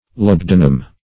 Labdanum \Lab"da*num\, n. (Bot.)